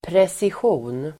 Uttal: [presisj'o:n]